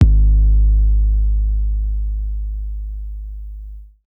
SouthSide Kick (19).wav